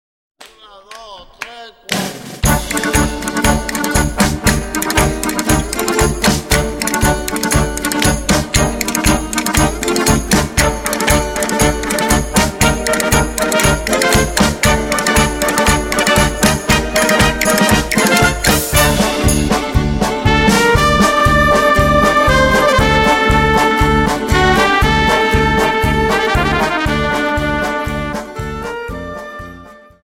Dance: Paso Doble